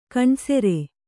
♪ kaṇsere